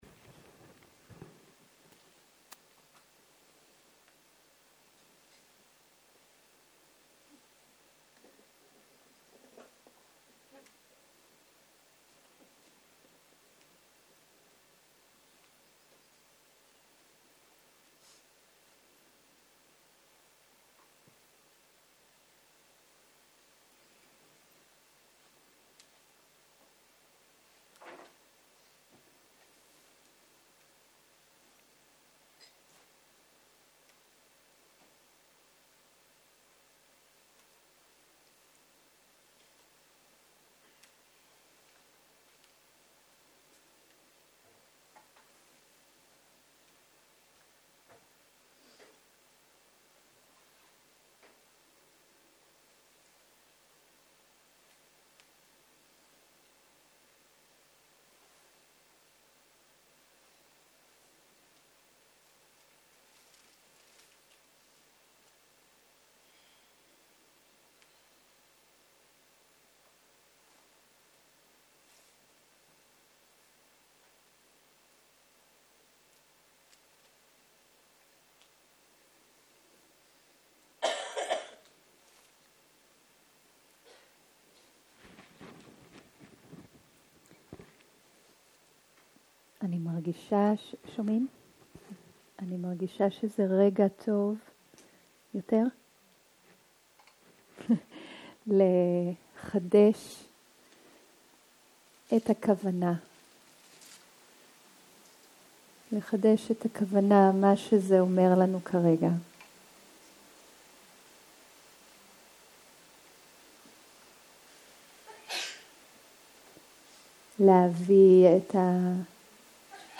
11.03.2023 - יום 3 - בוקר - מדיטציה מונחית, שאלות תשובות - הקלטה 4
סוג ההקלטה: מדיטציה מונחית